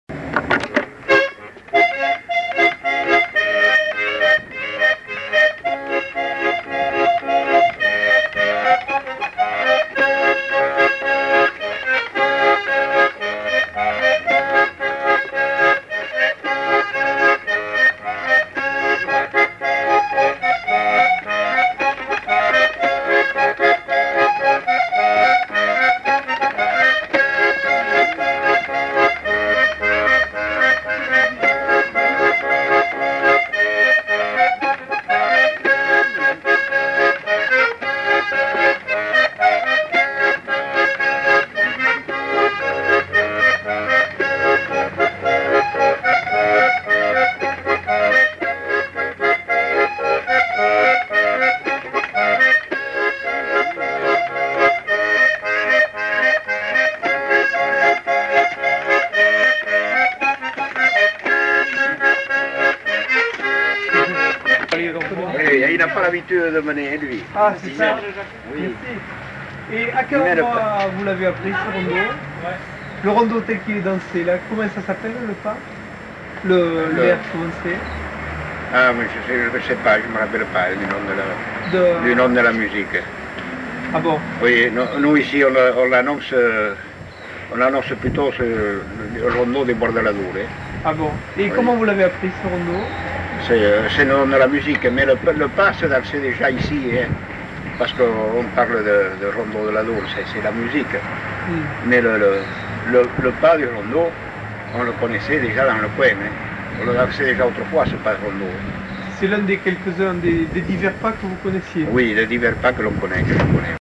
Lieu : Mimizan
Genre : morceau instrumental
Instrument de musique : accordéon diatonique
Danse : rondeau